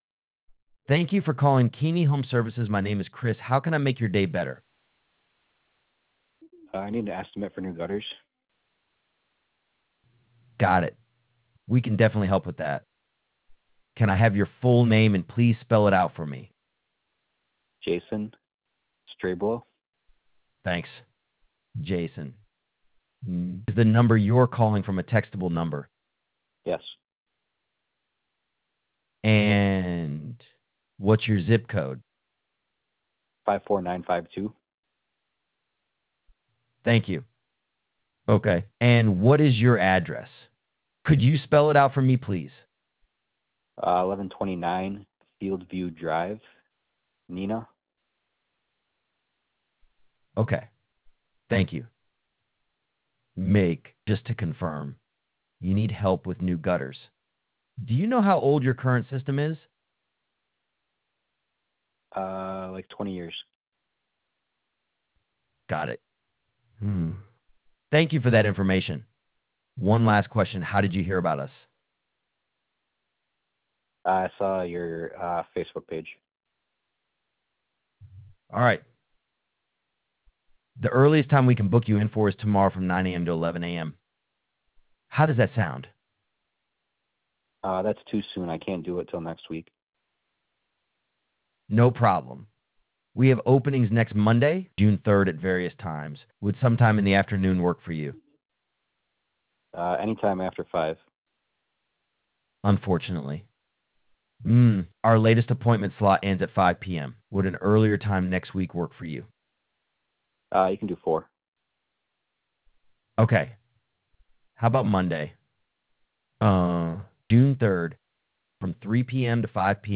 Keeney-Home-Services-AI-Voice-Sample.wav